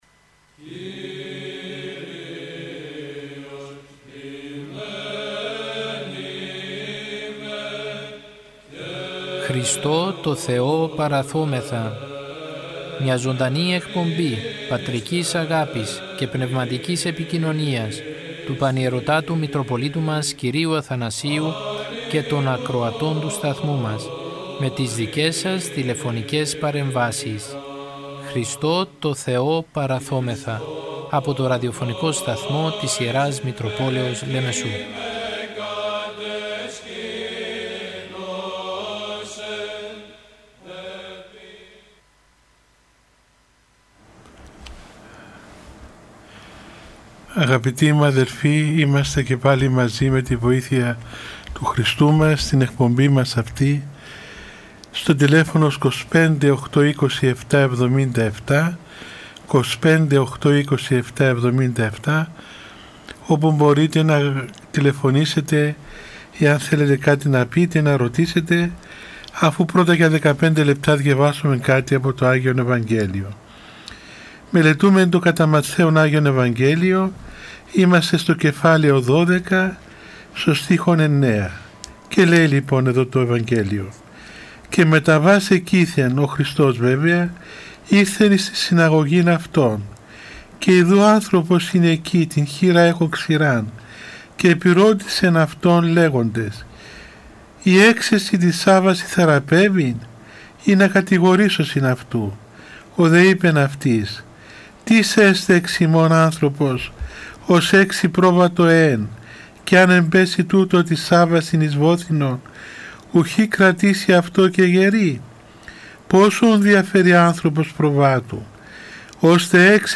Η Ιερά Μητρόπολη Λεμεσού μετά από το διάταγμα που εξέδωσε η Κυβέρνηση για την αντιμετώπιση της πανδημίας του κορωνοϊού που περιορίζει τις μετακινήσεις και την προσέλευση των πιστών στους ναούς, θέλοντας να οικοδομήσει και να στηρίξει ψυχικά και πνευματικά όλους τους πιστούς παρουσιάζει καθημερινά ζωντανές εκπομπές με τον Πανιερώτατο Μητροπολίτη Λεμεσού κ. Αθανάσιο, με τίτλο «Χριστώ τω Θεώ παραθώμεθα». Ο Πανιερώτατος απαντά στις τηλεφωνικές παρεμβάσεις των ακροατών του Ραδιοφωνικού Σταθμού της Ι. Μ. Λεμεσού και απευθύνει λόγο παρηγορητικό, παραμυθητικό και ποιμαντικό.